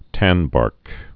(tănbärk)